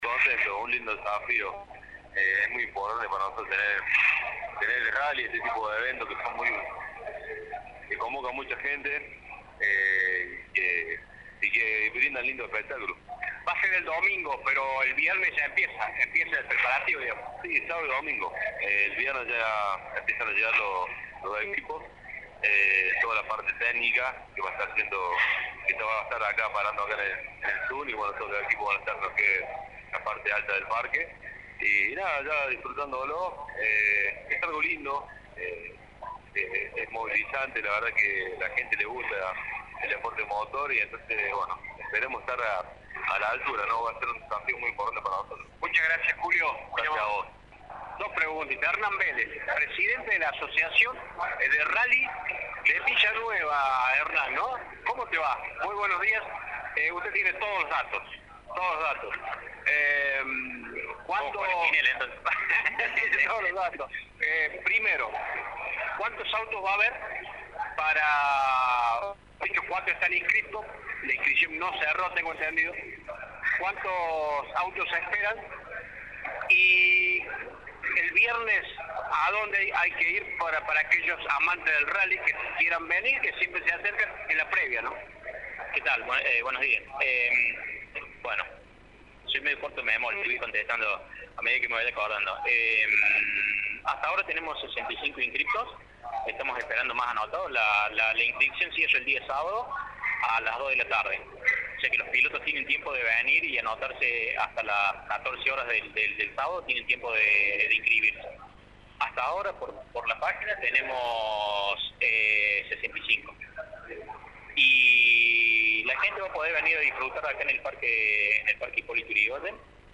rally-conferencia.mp3